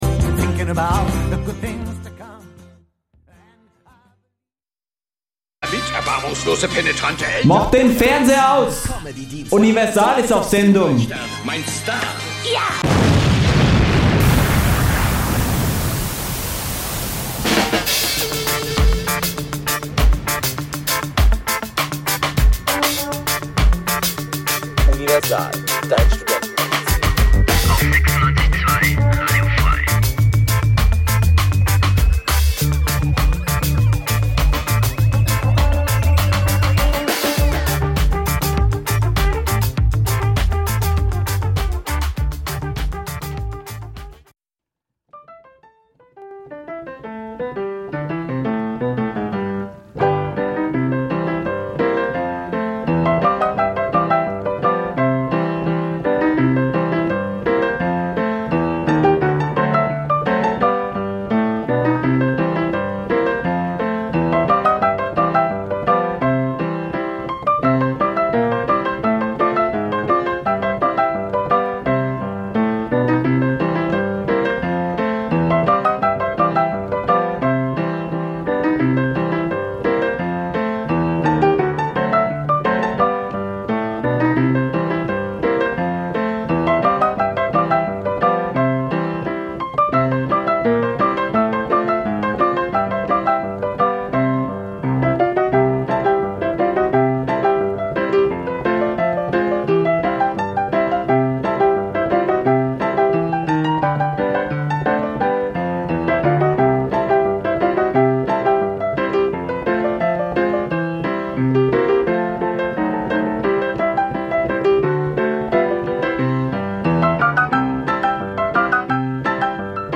Die Sendungen werden gemeinsam vorbereitet - die Beitr�ge werden live im Studio pr�sentiert.